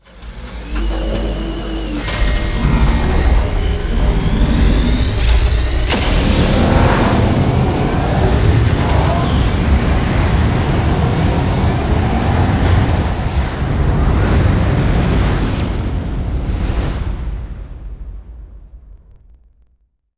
shipRift.wav